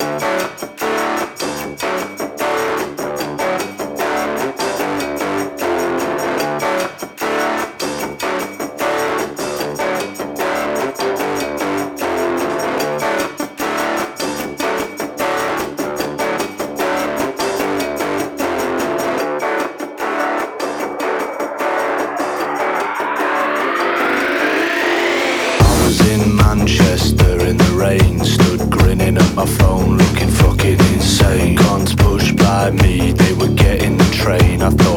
Alternative Electronic
Жанр: Альтернатива / Электроника